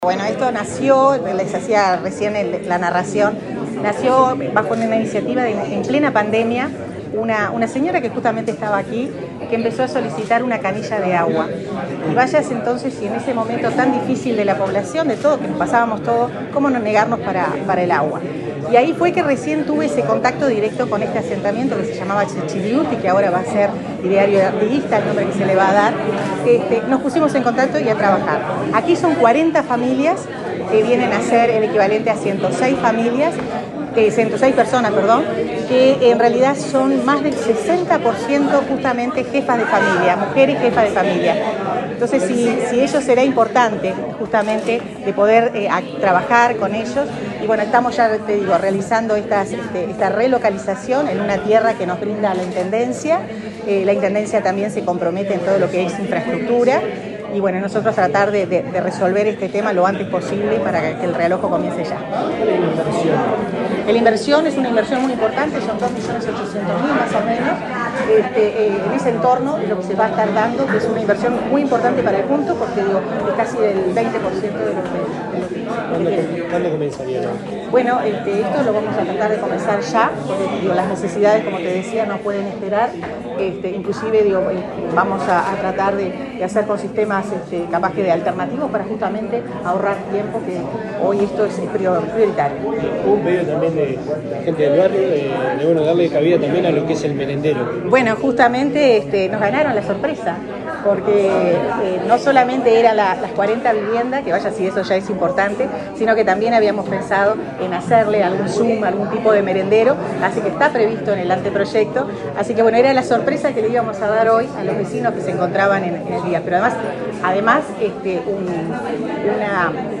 Declaraciones de la ministra de Vivienda, Irene Moreira